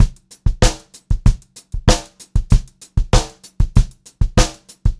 96POPBEAT1-L.wav